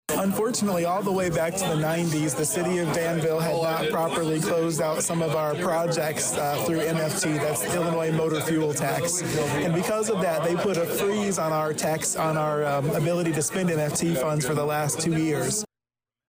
As Mayor Williams reminded everyone, this means a bureaucratic problem the city’s had for a while has now come to an end.